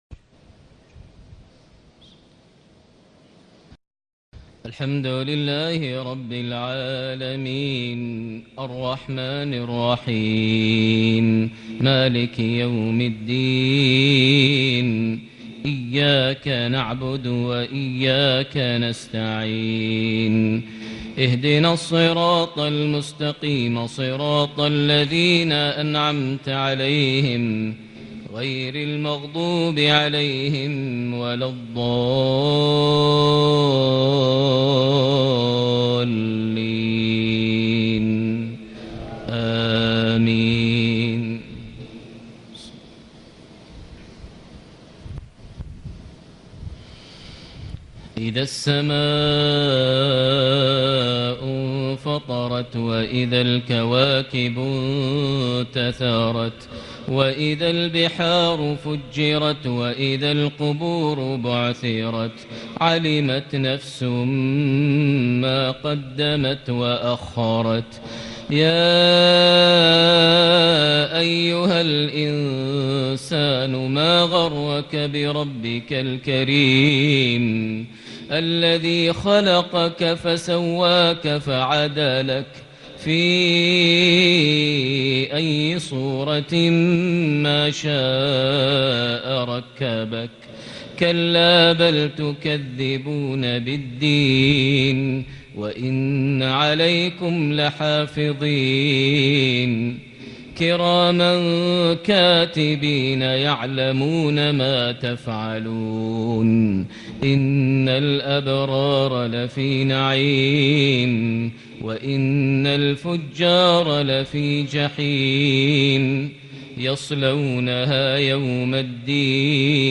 صلاة العشاء ٢٧ ذو القعدة ١٤٣٨هـ سورة الإنفطار > 1438 هـ > الفروض - تلاوات ماهر المعيقلي